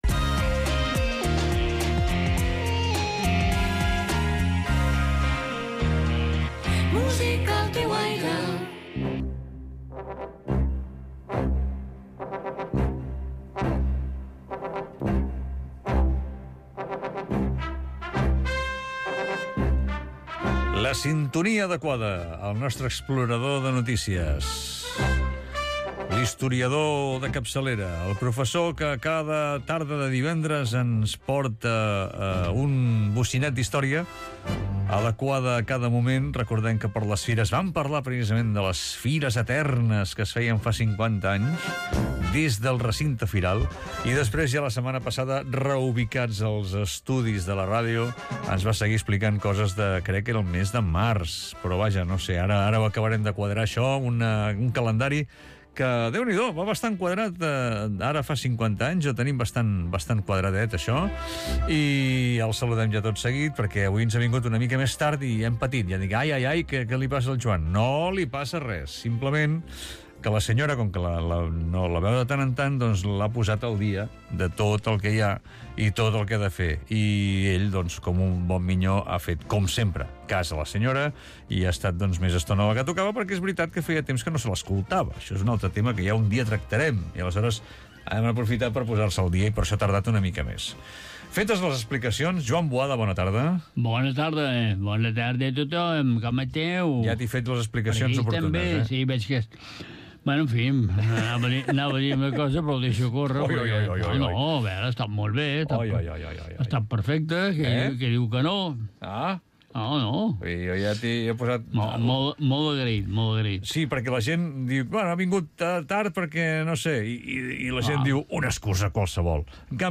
Entrevista als Acabamos de llegar 27/6/25